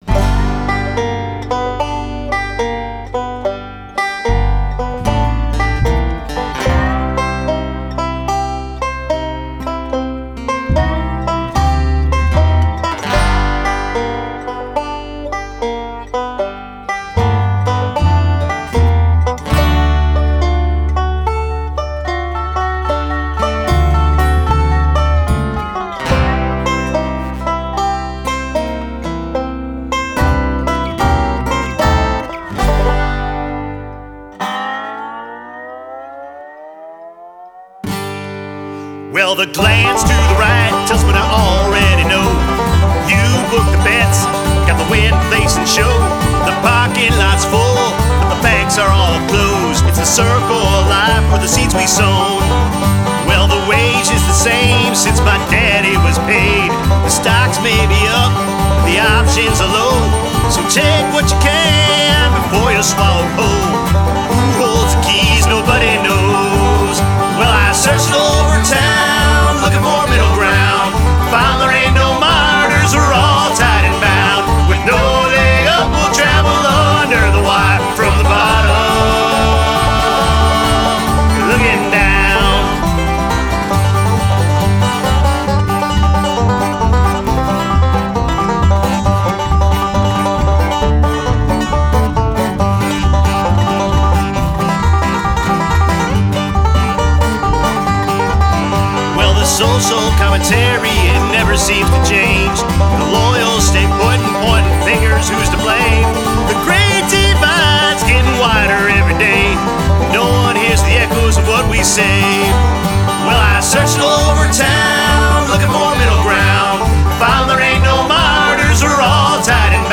Genre: Americana.